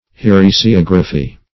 Meaning of heresiography. heresiography synonyms, pronunciation, spelling and more from Free Dictionary.